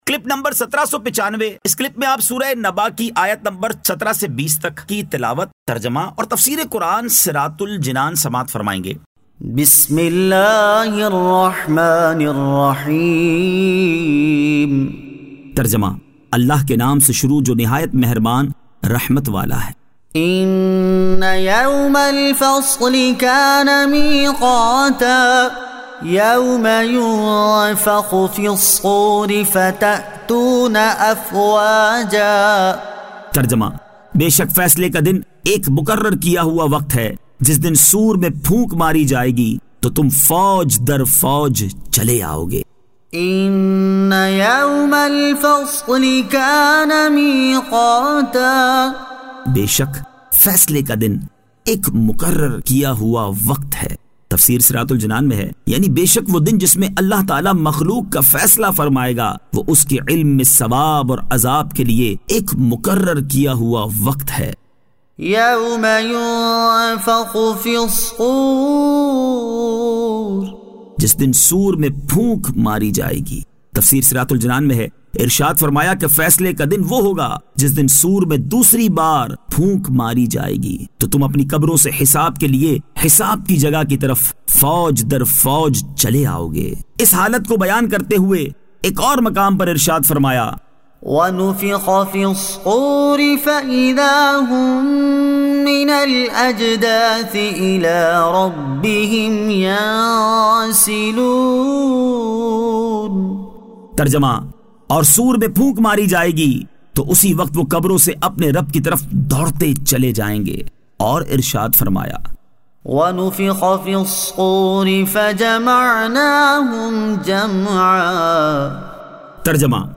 Surah An-Naba 17 To 20 Tilawat , Tarjama , Tafseer